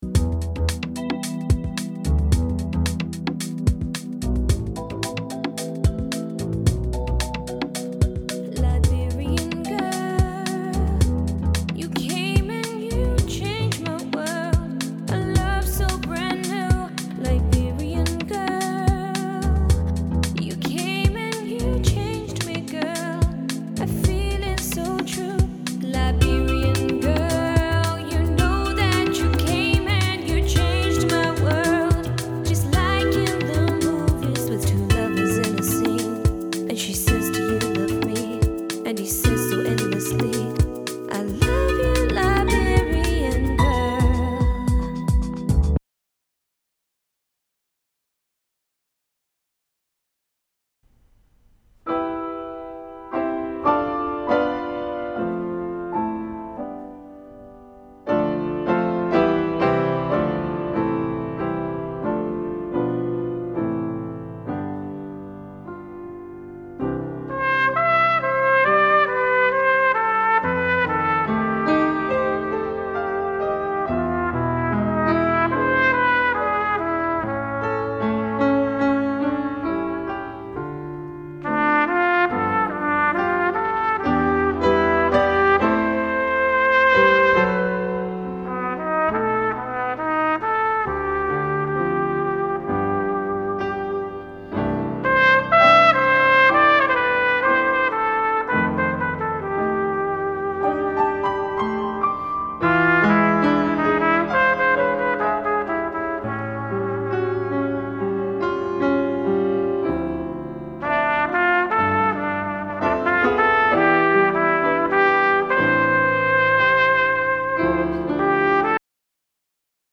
The study track I have provided (all music I produced) has several short selections of music with different feels and styles.